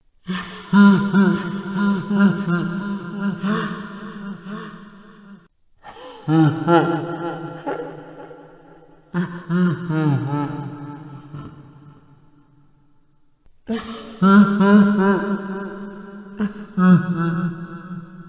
Звук смеха Фредди